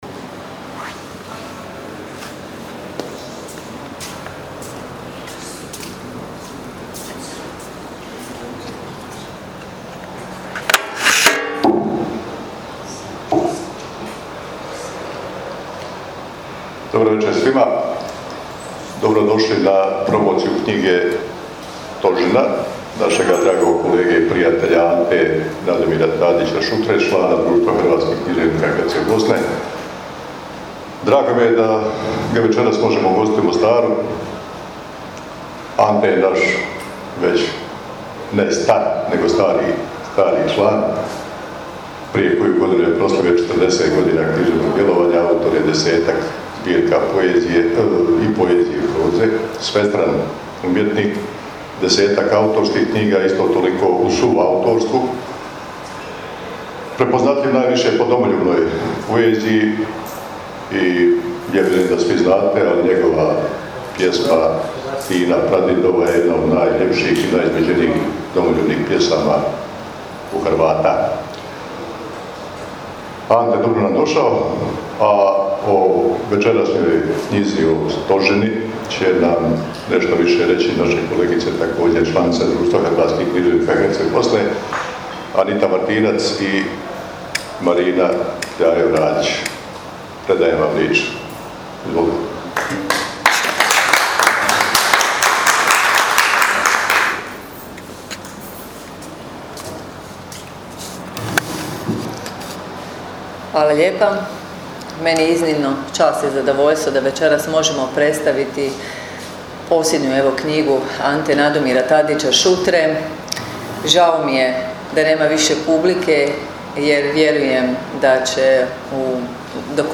Na poveznici možete poslušati večerašnje predstavljanje: